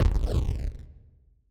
TankExplosion.wav